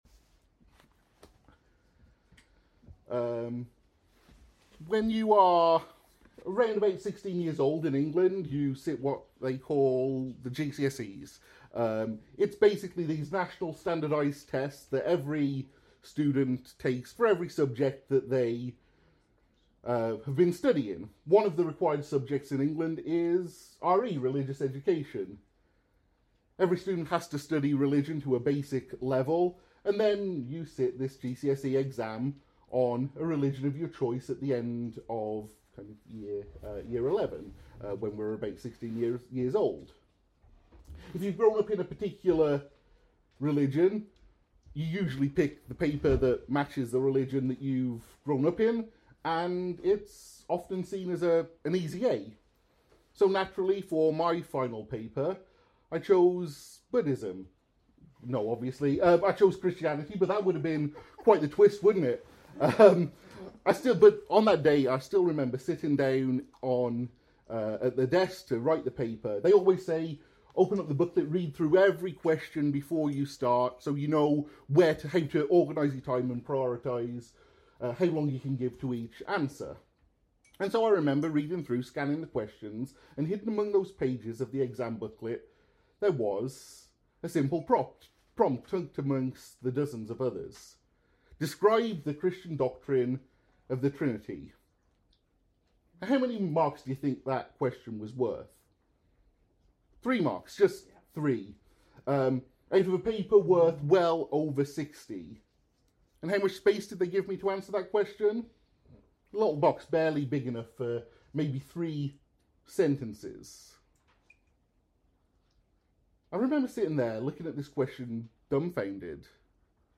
Rooted in Scripture from Genesis to Revelation, this sermon unpacks the biblical teaching that there is one God, who eternally exists in three persons: Father, Son, and Holy Spirit.